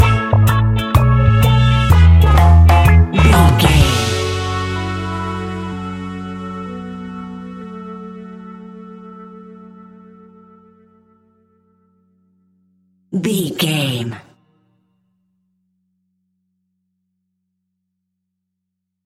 Classic reggae music with that skank bounce reggae feeling.
Uplifting
Aeolian/Minor
F#
laid back
chilled
drums
skank guitar
hammond organ
percussion
horns